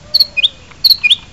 Nada notifikasi Burung bersiu
Genre: Nada dering binatang
Detail: Suara notifikasi siulan burung akan membawa Anda lebih dekat dengan alam, menghadirkan suasana yang menenangkan dan menyegarkan setiap kali ada pesan masuk ke ponsel Anda. Setiap siulan yang terdengar seakan membawa Anda ke tengah hutan tropis yang asri, menghadirkan pengalaman baru yang unik dan berbeda dari nada notifikasi pada umumnya.
nada-notifikasi-burung-bersiu.mp3